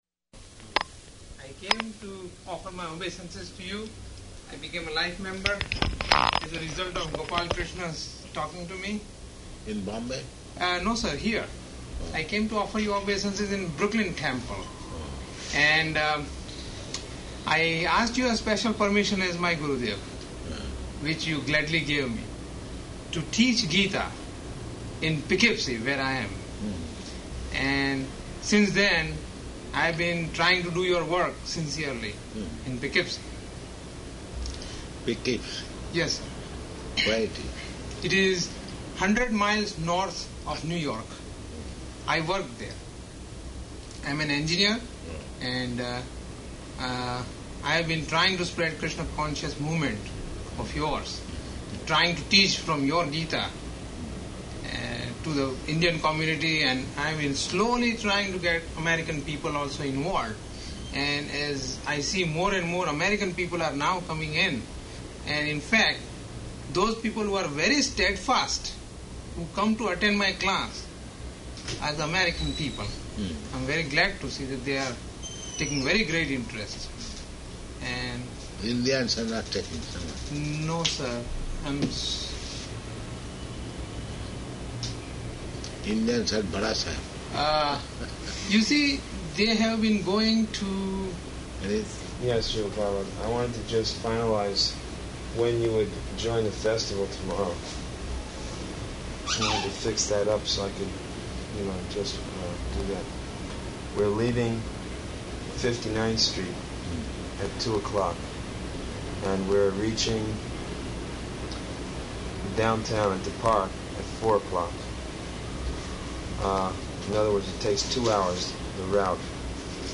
Room Conversation
Room Conversation --:-- --:-- Type: Conversation Dated: July 17th 1976 Location: New York Audio file: 760717R1.NY.mp3 Indian man: I came to offer my obeisances to you.